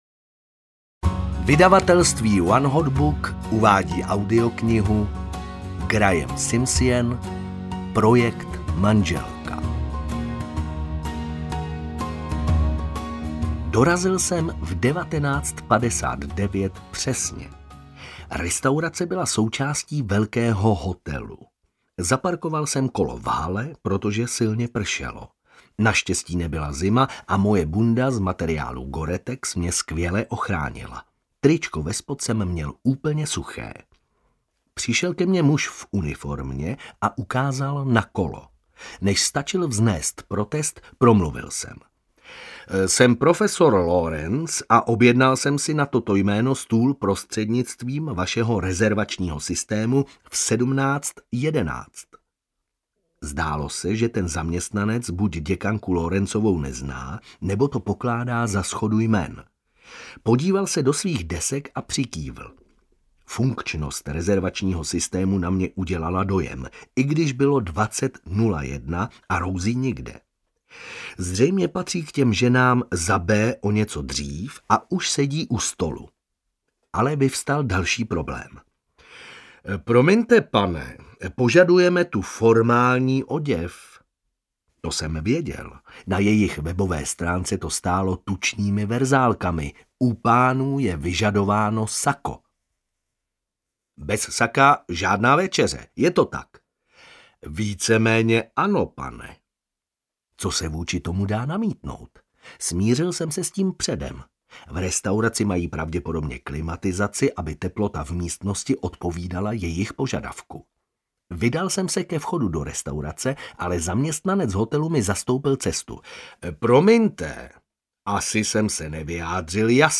Projekt manželka audiokniha
Ukázka z knihy